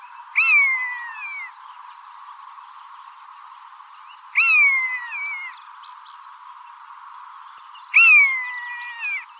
chant buse